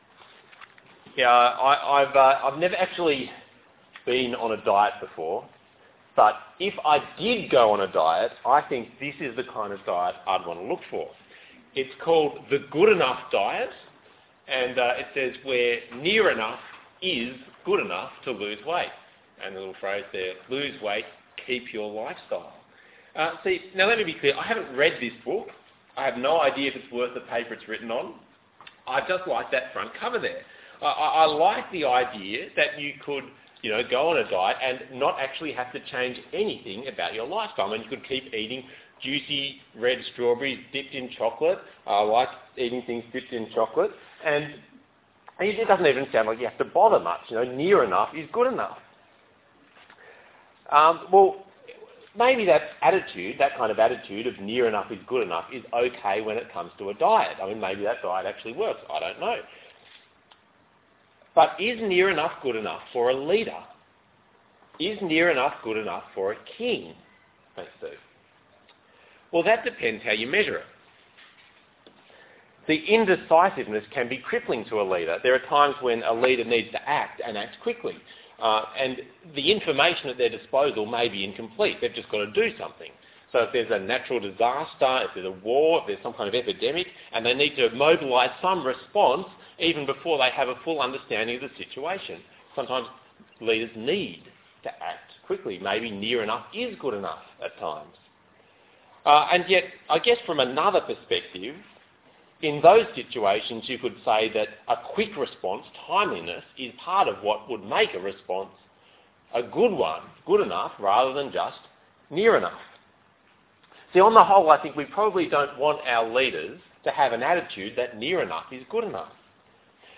Passage: 1 Samuel 15:1-35 Talk Type: Bible Talk